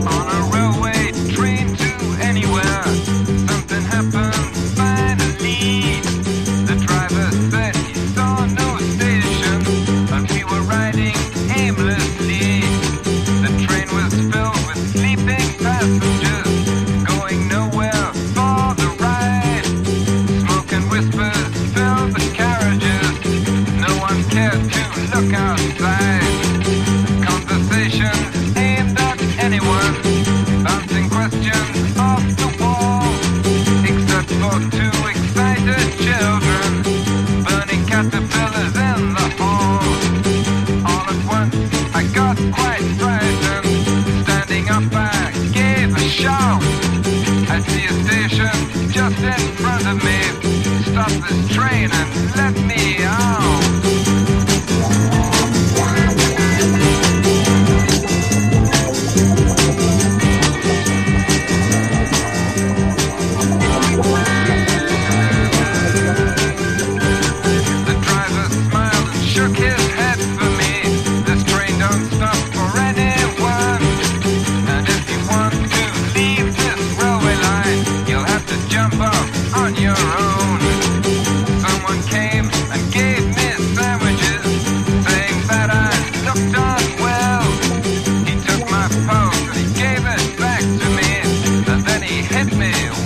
マジカルなカンタベリー・ロック/屈折サイケデリック・ポップ最高峰！